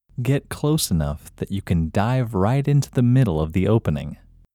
IN – Second Way – English Male 12